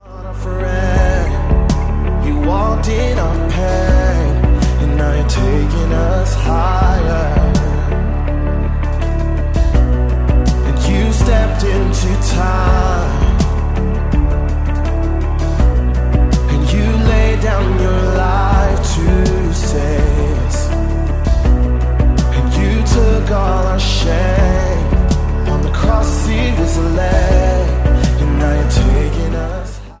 2016-os dupla koncertfelvétele